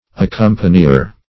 Accompanier \Ac*com"pa*ni*er\, n. He who, or that which, accompanies.